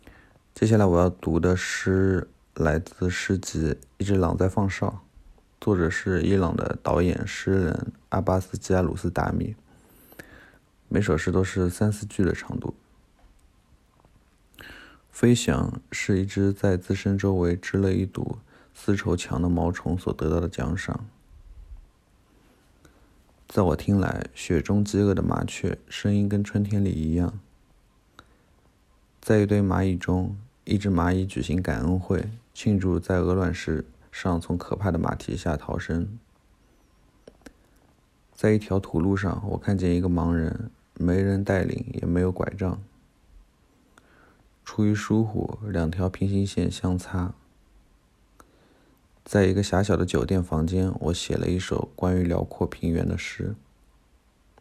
接下来我要读的诗来自诗集《一只狼在放哨》，作者是伊朗的导演/诗人阿巴斯·基阿鲁斯达米，每首诗都是三四句的长度。